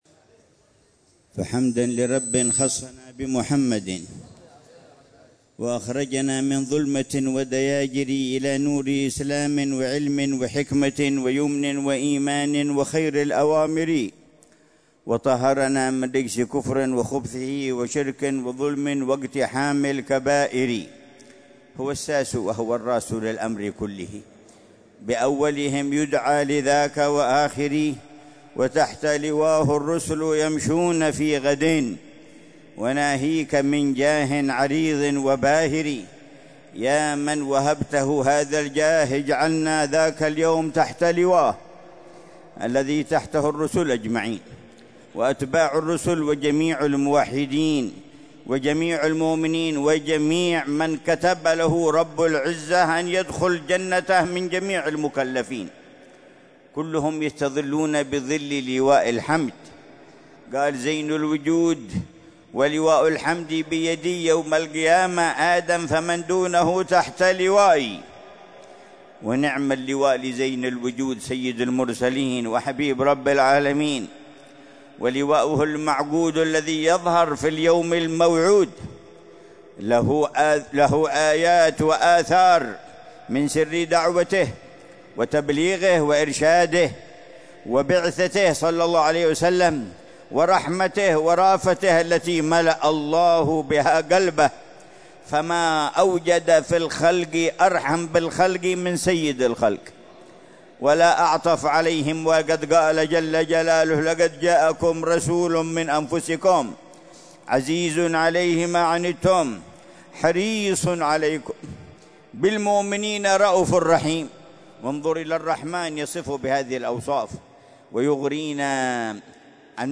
مذاكرة الحبيب عمر بن حفيظ في المولد السنوي في مسجد الفتح للإمام عبد الله بن علوي الحداد، بمدينة تريم، حضرموت، ضحى الأحد 29 ربيع الأول 1447هـ